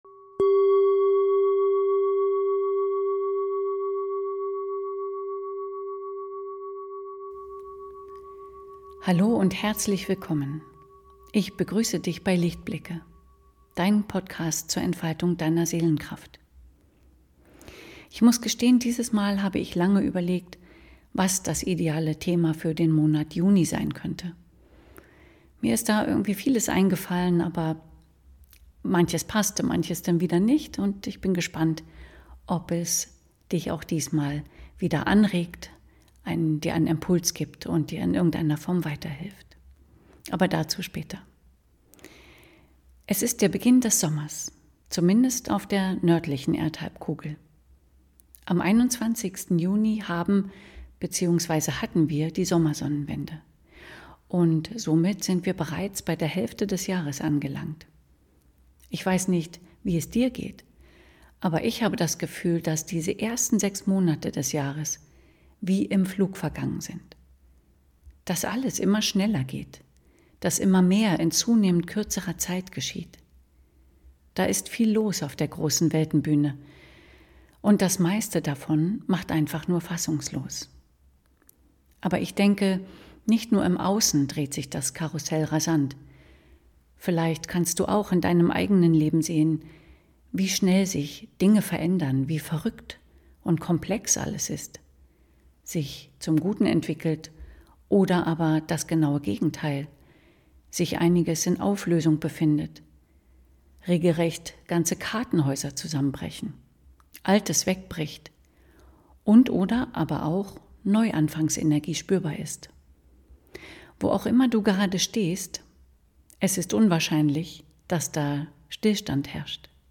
Immer! - mit anschließender Klang...